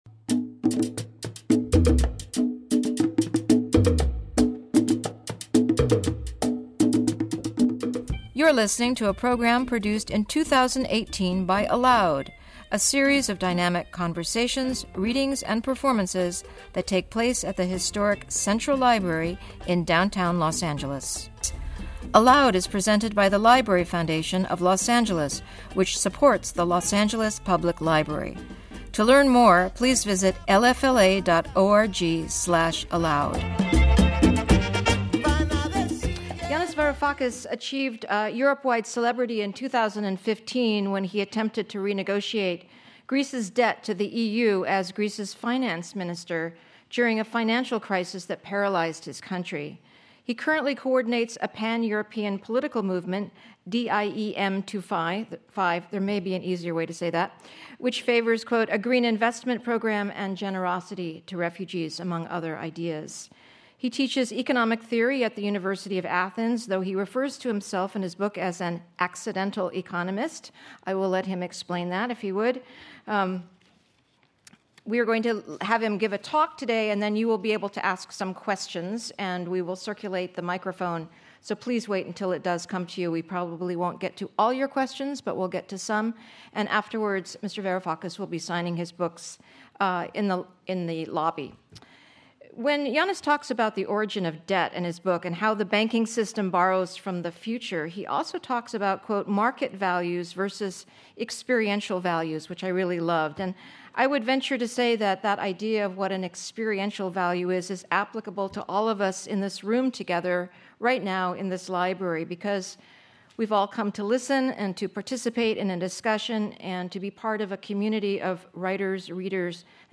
email Yanis Varoufakis A Lecture Thursday, May 17, 2018 1:00:47 ALOUD Listen: play pause stop / varoufakis.mp3 Listen Download this episode Episode Summary What happens when you take on the establishment?
In a special lunchtime talk, Varoufakis offers an inside look at an extraordinary story fueled by hypocrisy and betrayal that shook the global establishment to its foundations and shares an urgent warning about how the policies once embraced by the EU and the White House have spawned instability throughout the Western world.